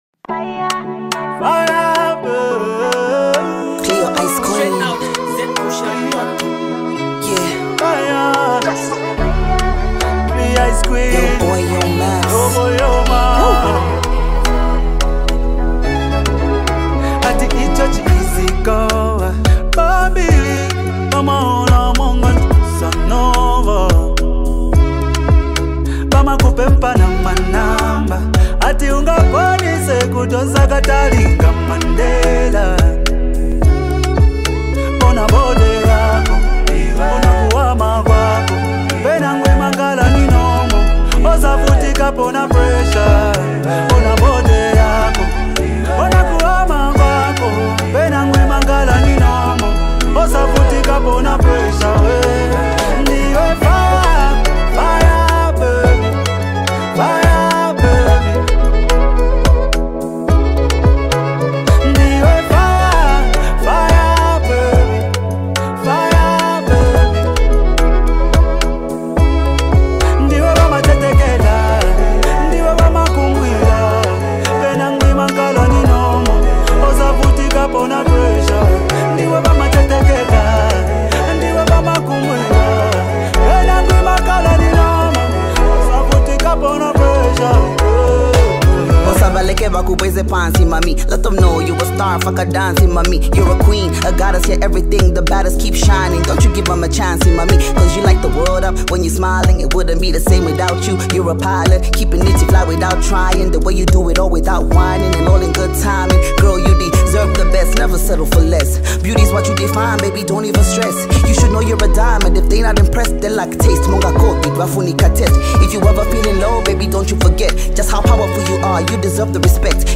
electric violin